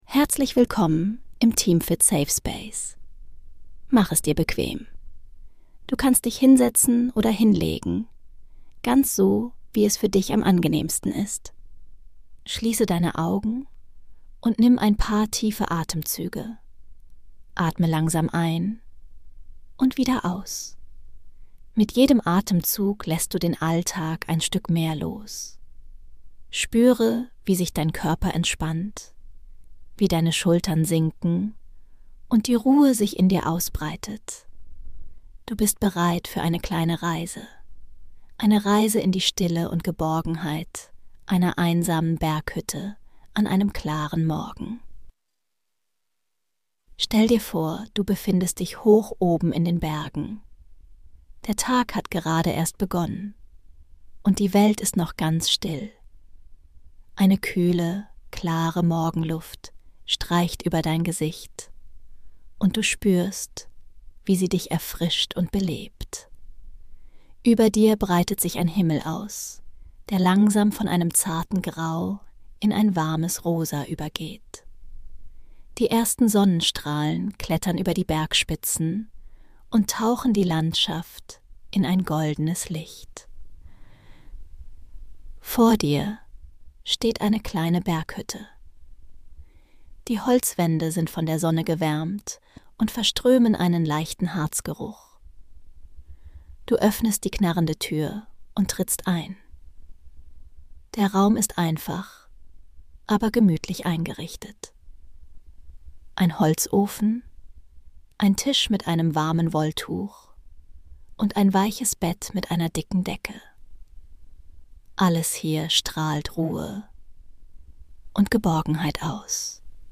In dieser Traumreise nehmen wir dich mit auf eine einsame Berghütte